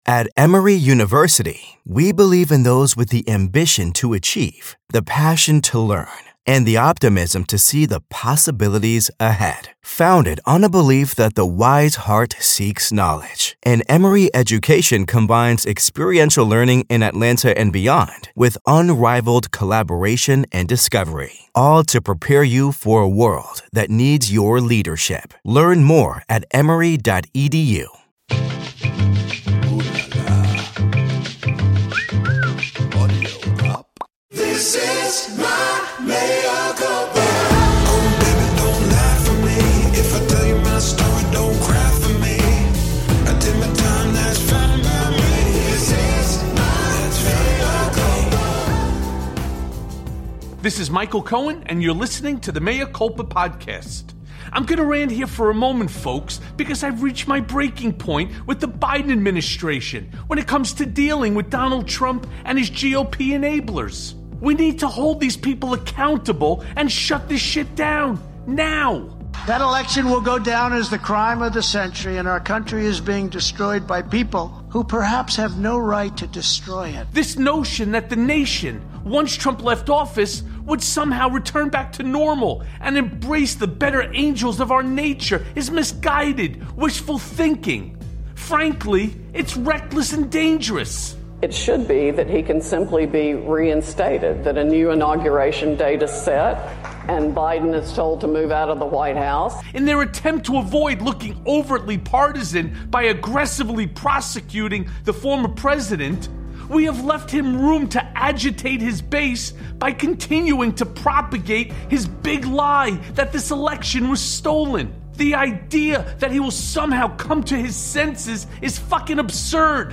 A Conversation With MSNBC’s Joy Reid
The GOP have dug in their heels behind the ex-liar-in-chief, who returned to the stage in North Carolina over the weekend. Facebook has drawn a line in the social media sand and Michael chats with the brilliant and insightful Joy Reid from MSNBC’s Reid Out.